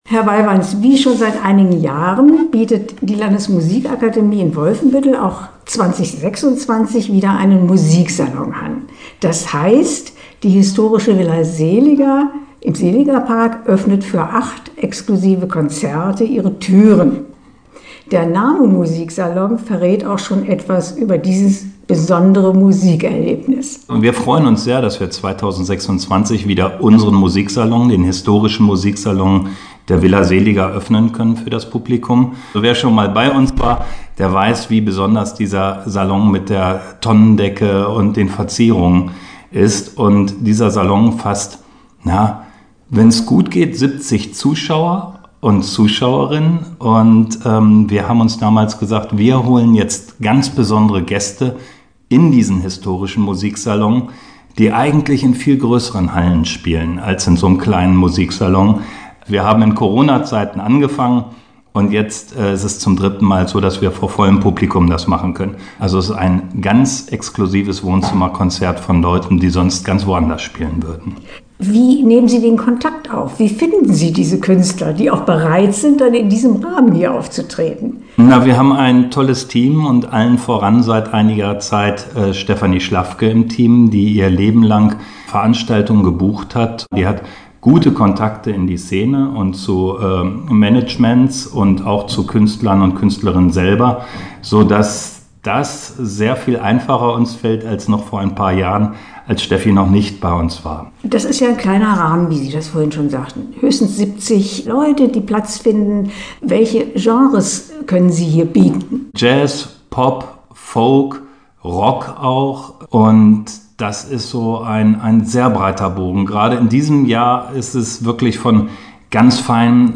Interview-Musiksalon-WF-2026.mp3